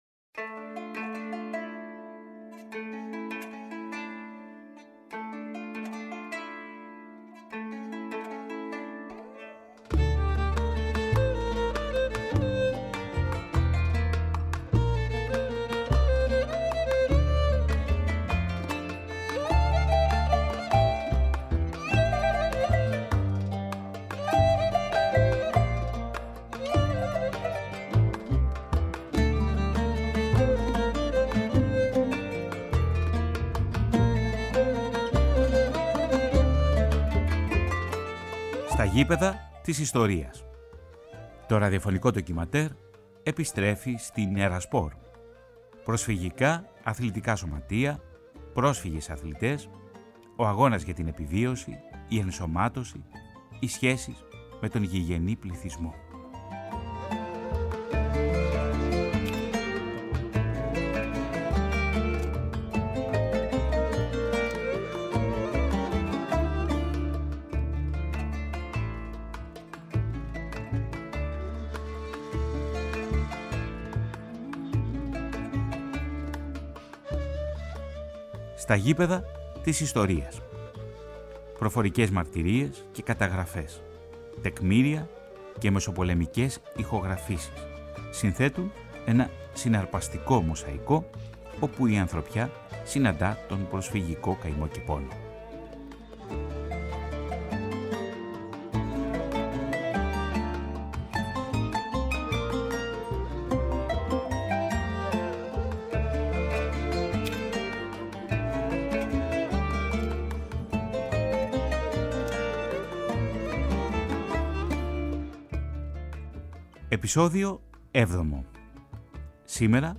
Το αθλητικό ραδιοφωνικό ντοκιμαντέρ επιστρέφει στην ΕΡΑ ΣΠΟΡ και τα επόμενα δύο επεισόδια είναι αφιερωμένα στον προσφυγικό Βόλο, που αποτέλεσε ένα από τα σημαντικότερα λιμάνια εισδοχής προσφύγων μετά τη Μικρασιατική Καταστροφή.
Στο 7 ο και 8 ο ραδιοφωνικό ντοκιμαντέρ της σειράς, οι ακροατές της ΕΡΑ ΣΠΟΡ θα έχουν την ευκαιρία να γνωρίσουν την προσφυγική ομάδα «Νίκη» μέσα από τις προφορικές μαρτυρίες των παιχτών της, που αγωνίστηκαν με τα χρώματα της ομάδας τα ύστερα μεταπολεμικά χρόνια.